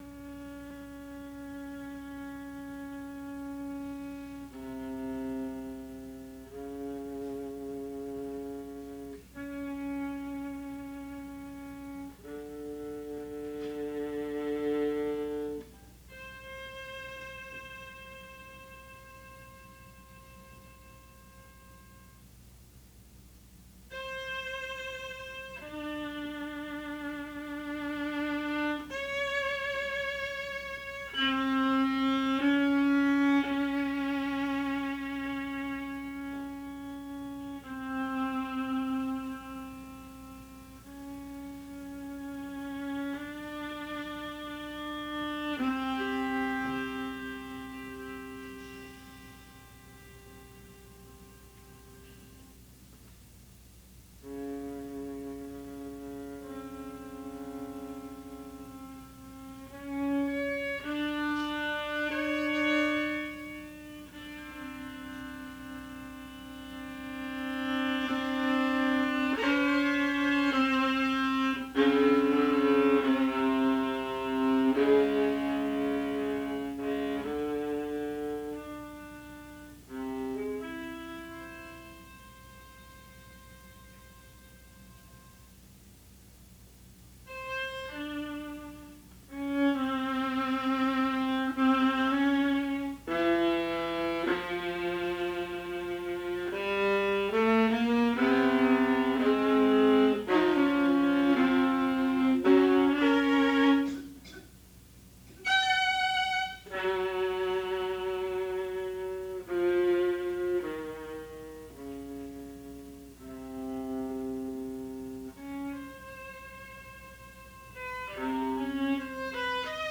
Works for Solo Viola
Sven-Reher-Sonata-for-Solo-Viola-Enigma-First-Movement.mp3